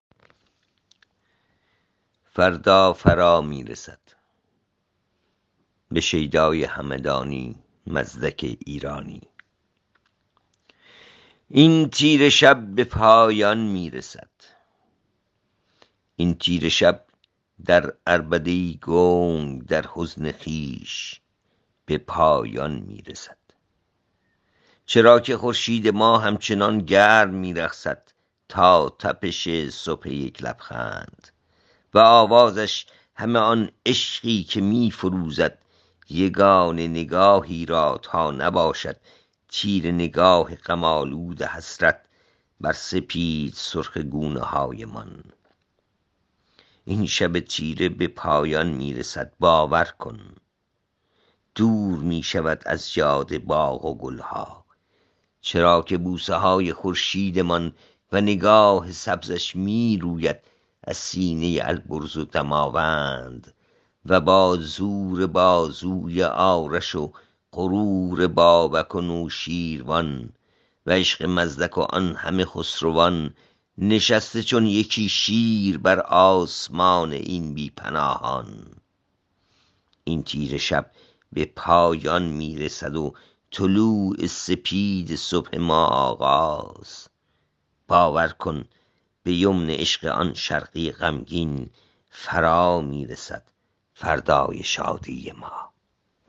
این شعر را با صدای شاعر از این‌جا بشنوید